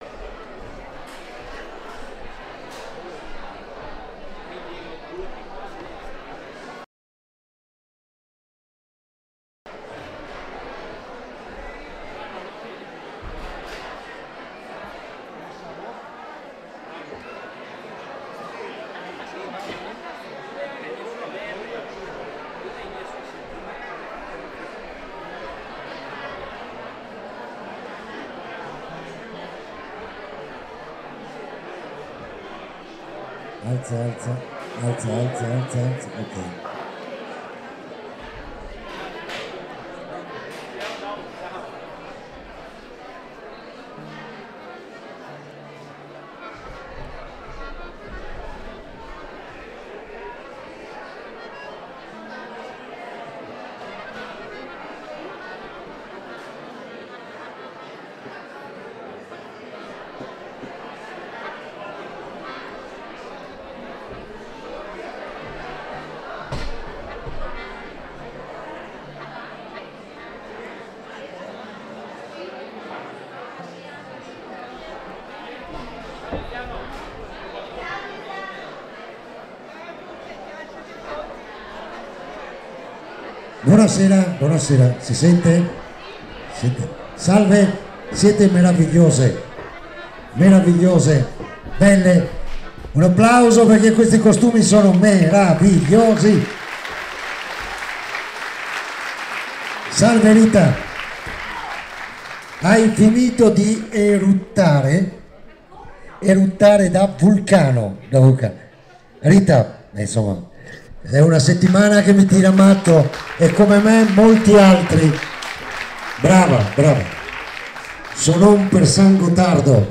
SONON PAR SAN GOTTARDO – LA DIRETTA - Radio Più
SOSPIROLO Appuntamento a Sospirolo con “Sonon par San Gottardo”, seconda edizione della serata musicale promossa per raccogliere fondi per il restauro della chiesa gravemente danneggiata da un fulmine nell’estate di due anni fa.